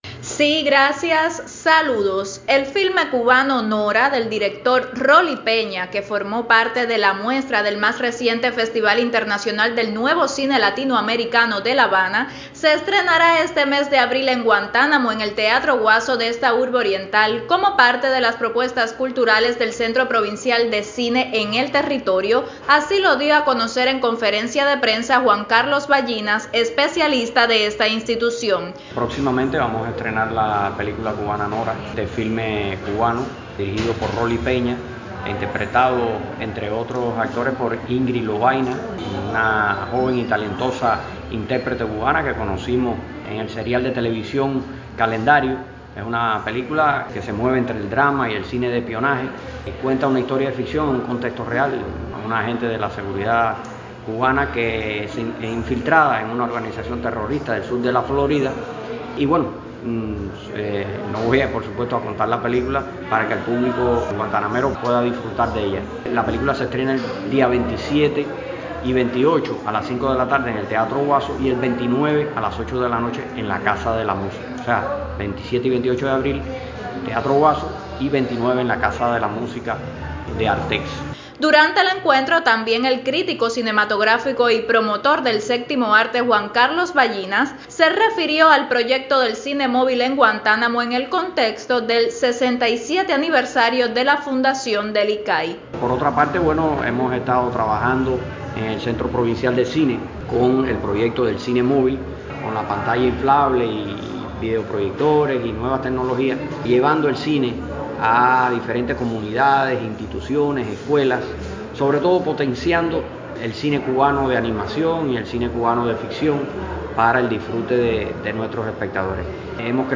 El filme cubano Nora se proyectará en el teatro Guaso de la ciudad capital y se espera que la historia cautive a diferentes públicos por la calidad de su elenco y el argumento que narra. Conozca más detalles a través del reporte